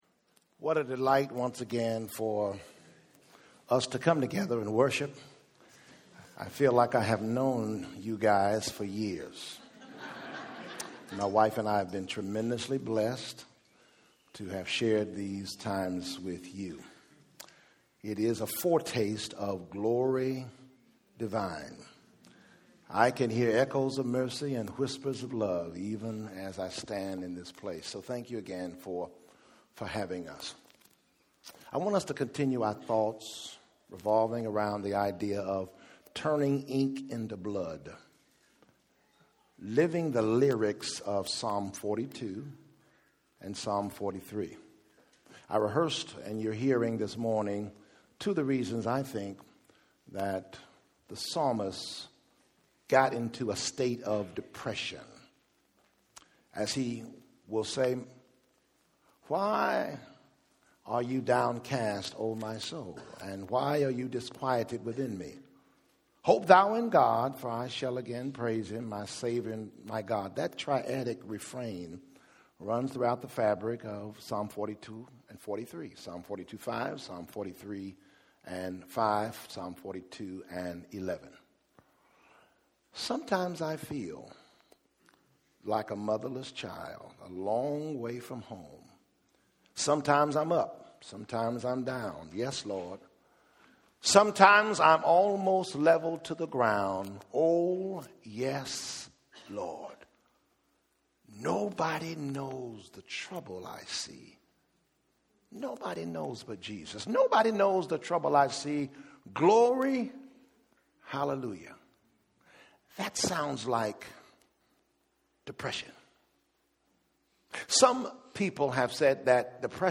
Preacher: Guest Preacher | Series: Church Retreat…